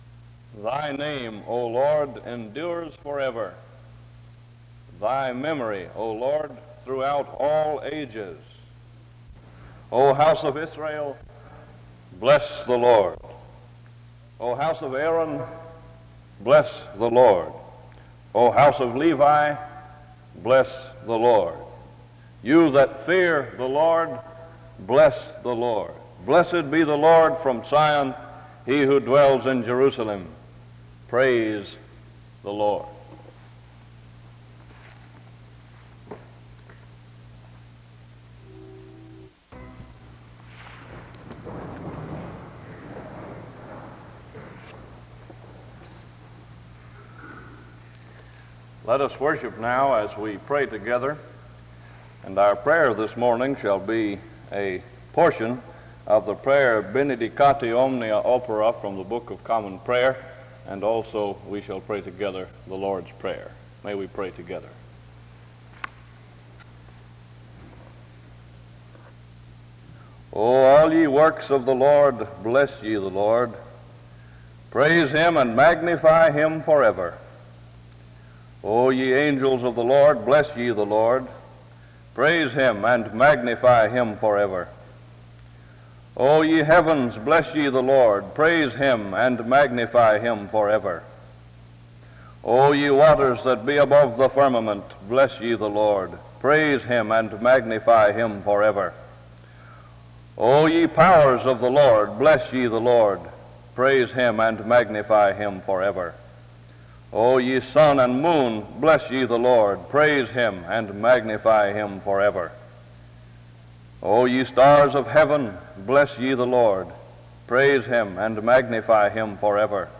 SEBTS Chapel
The service begins with scripture reading from 0:00-0:34. A prayer is offered from 0:48-3:45.
A responsive reading is read from 3:46-5:47.